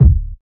Puffy Bass Drum Sample C Key 704.wav
Royality free kick drum sample tuned to the C note. Loudest frequency: 99Hz
puffy-bass-drum-sample-c-key-704-Kfk.mp3